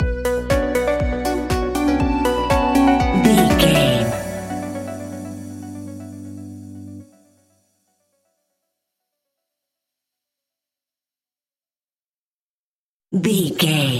Aeolian/Minor
Slow
groovy
peaceful
tranquil
meditative
smooth
drum machine
synthesiser
synth leads
synth bass